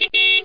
BEEP_07.mp3